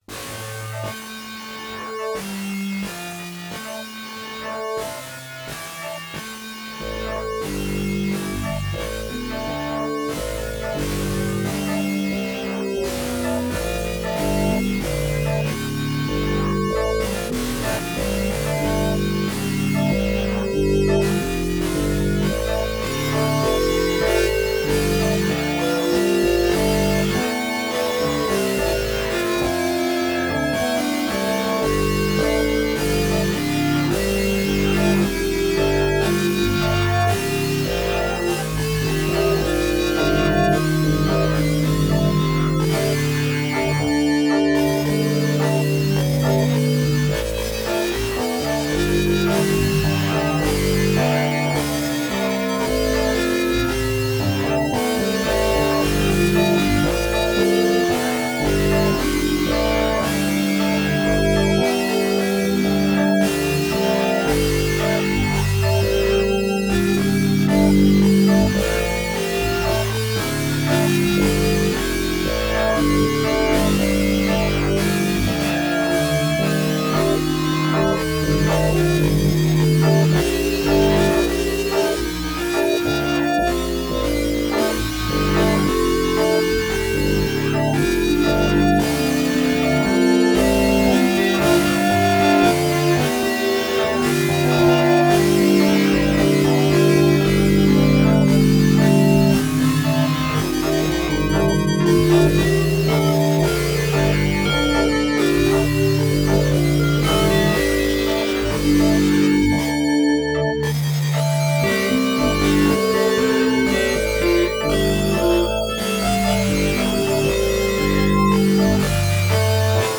Name of track is ‘Hocket’. Its a solo piece with me on all instruments (tiny cheap keyboard massively processed and a guitar) the object of the piece, if any, is to work towards a piece without chords, time signature or melody, but that somehow stays together.
Good noisy fun … but too short …
A very dense feeling. The different layers never exactly fit together, but I really wanted the song to continue so I can keep trying to figure it out.